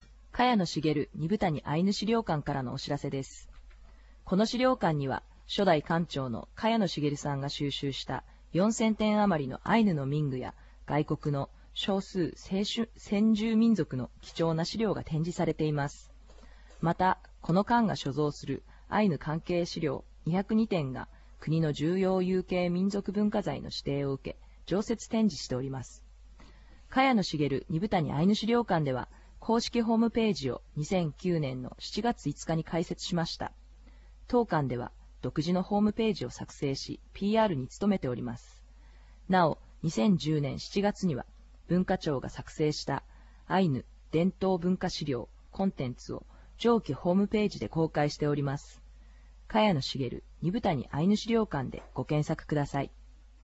司会進行